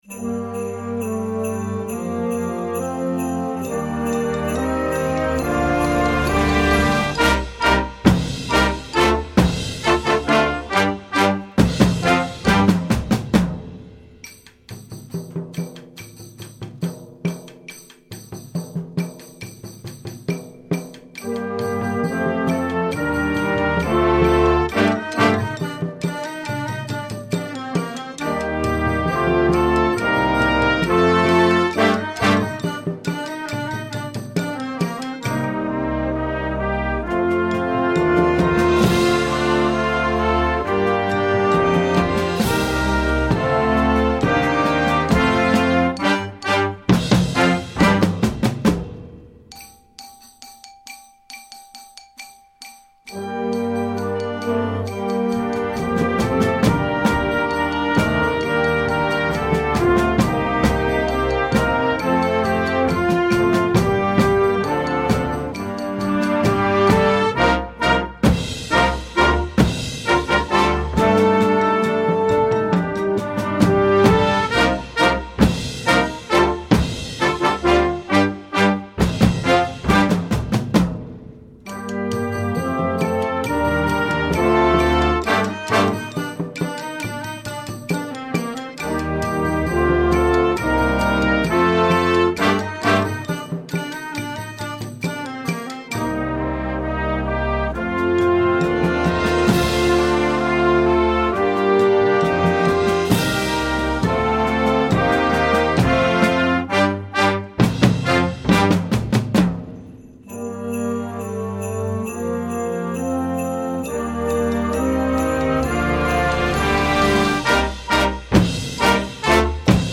Gattung: Blasmusik für Jugendkapelle
Besetzung: Blasorchester